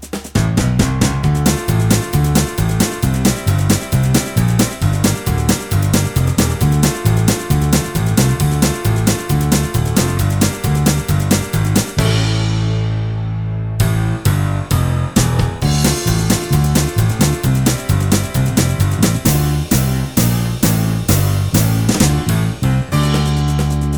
no Backing Vocals or fiddle Country (Male) 3:35 Buy £1.50